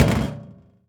metal_drum_impact_thud_07.wav